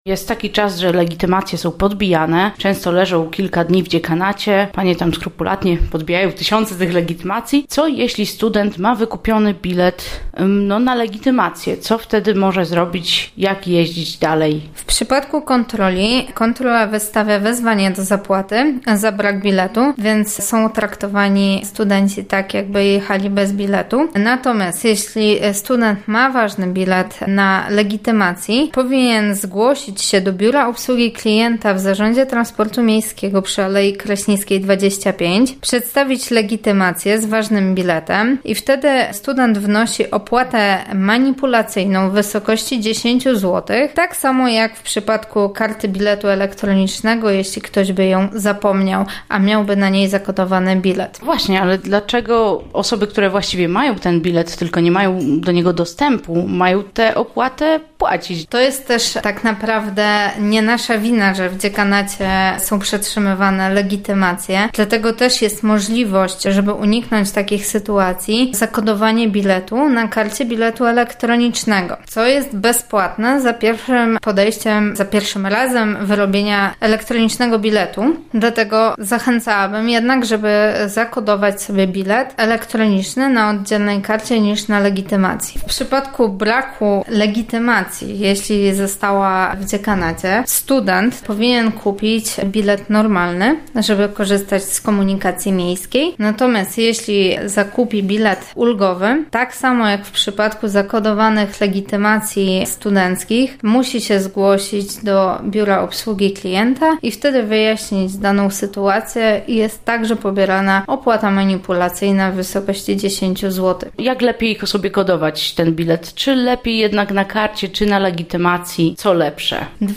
rozmawiała nasza reporterka